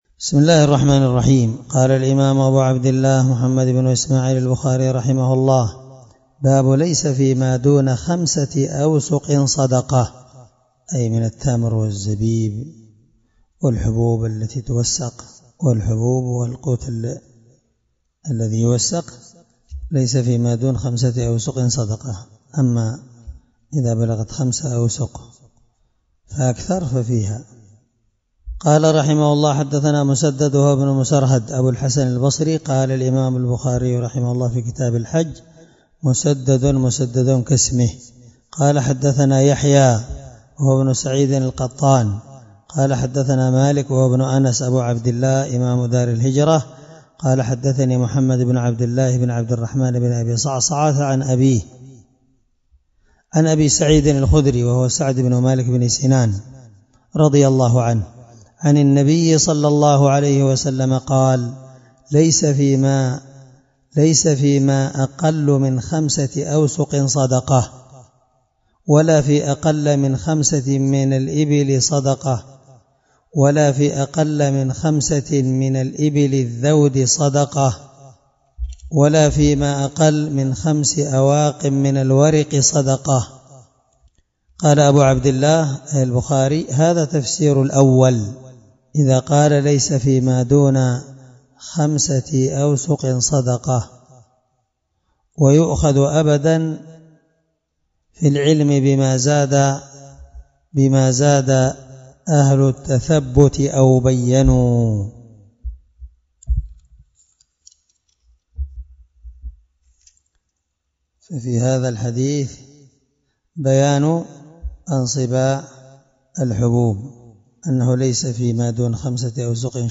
الدرس 54من شرح كتاب الزكاة حديث رقم(1484-1485 )من صحيح البخاري